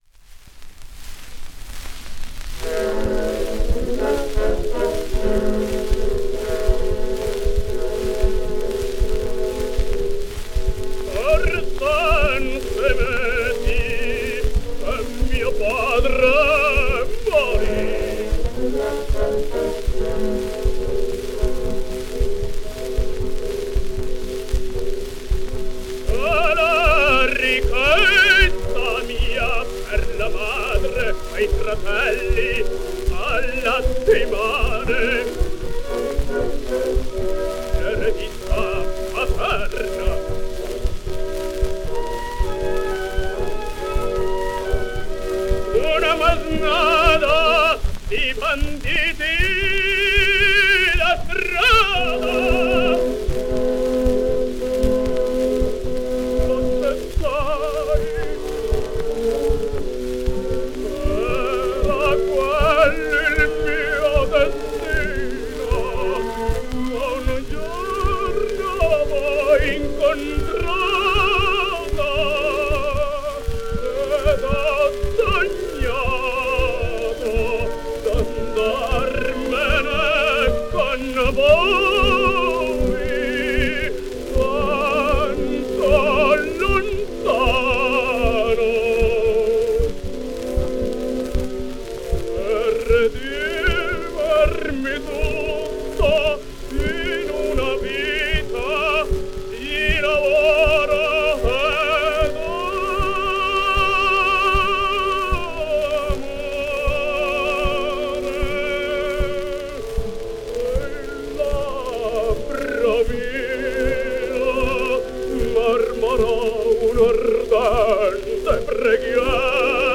Franco Lo Giudice sings La fanciulla del West: